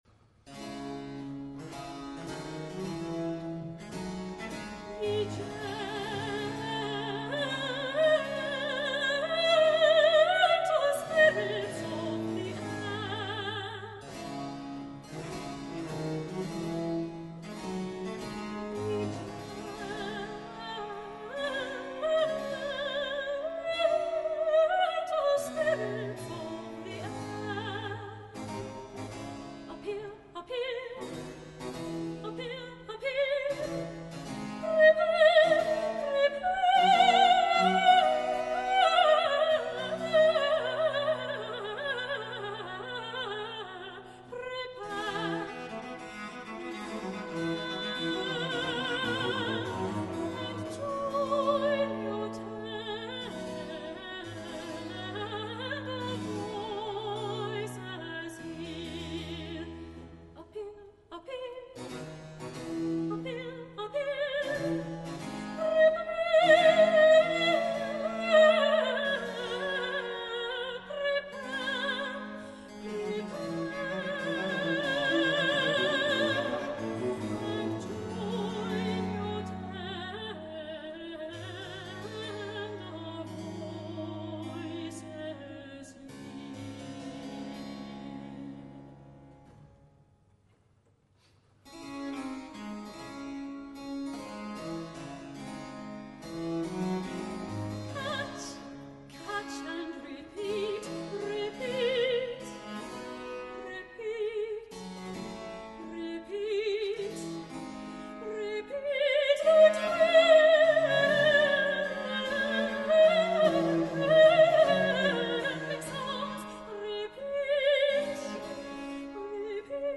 15b - The Fairy Queen, semi-opera, Z. 629- Act 3.
Solo.